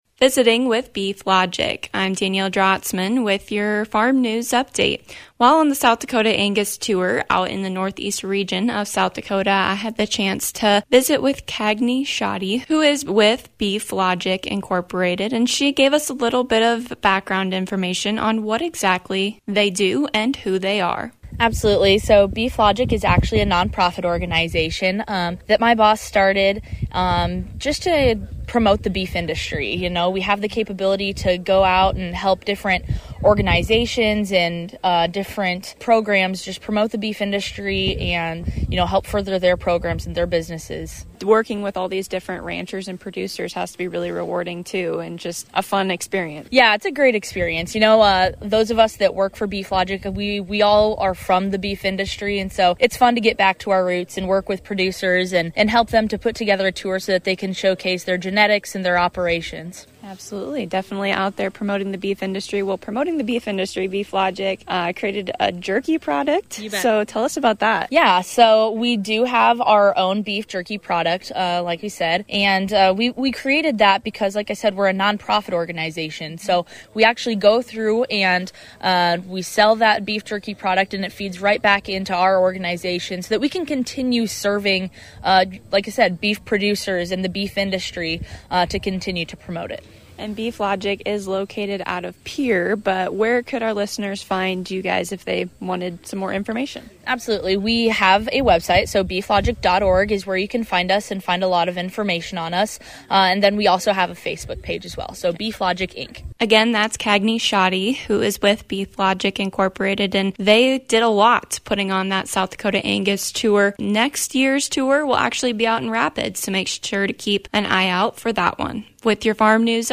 While on the South Dakota Angus Association’s Annual Cattle Tour this week, we got a chance to get to know Beef Logic a little better.